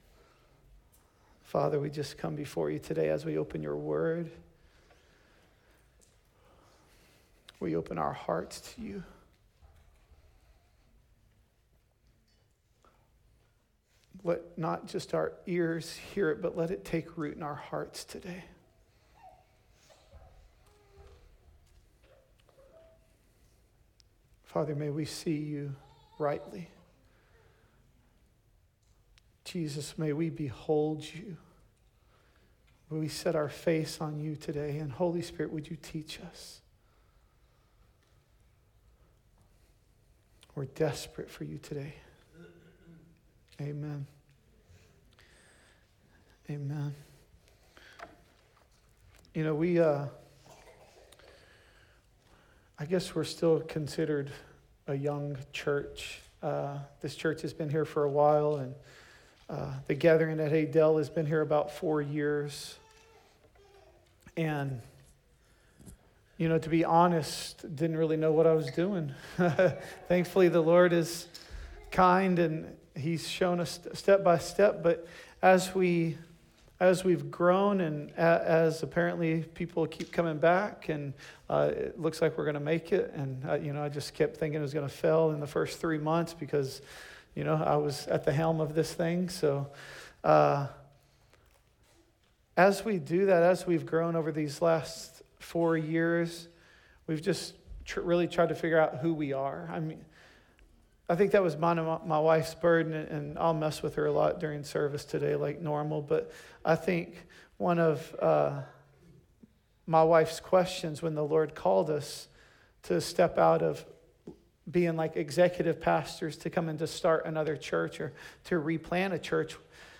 The Gathering at Adell Audio Sermons Encountering Jesus.